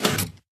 assets / minecraft / sounds / tile / piston / out.ogg